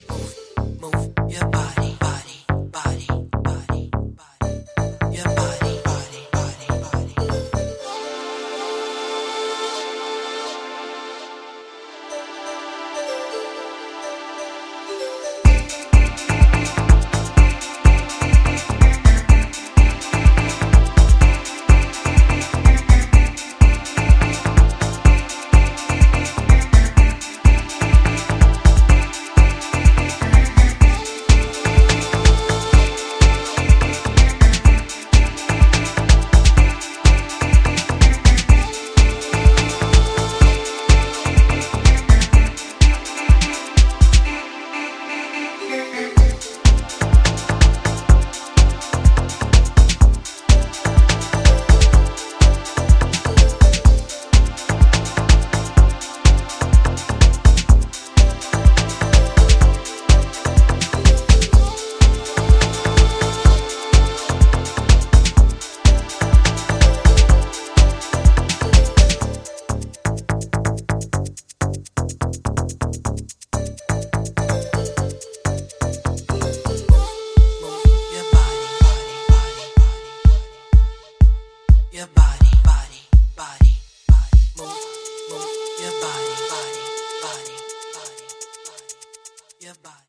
ドリーミーな90s イタリアン・ハウスに倣うディープ・ハウス群を展開しており